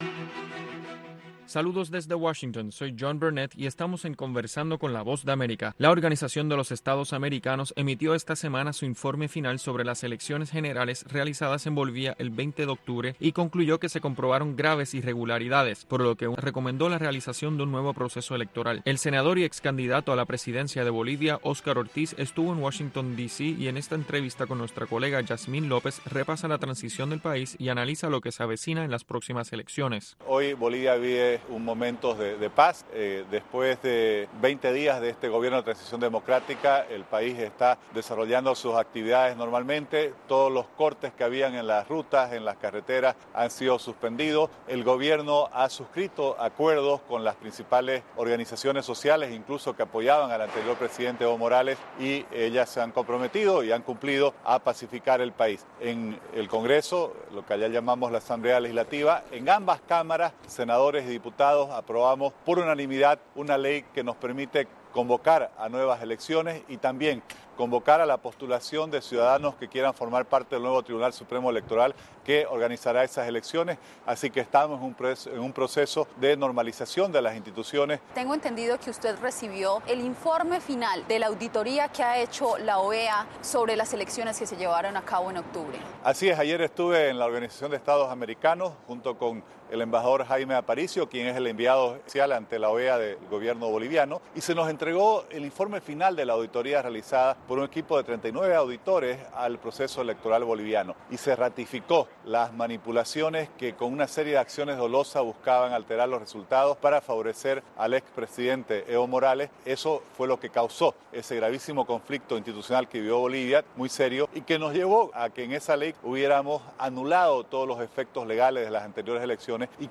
La Voz de América entrevista, en cinco minutos, a expertos en diversos temas.